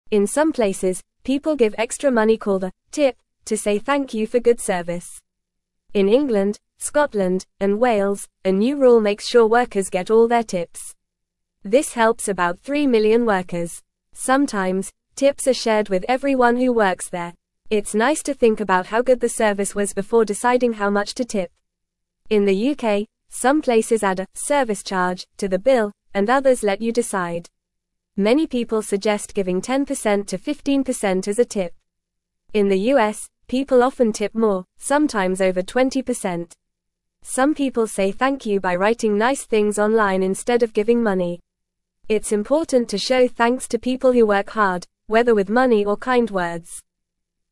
English-Newsroom-Beginner-FAST-Reading-Tips-and-Service-Charges-How-to-Say-Thanks.mp3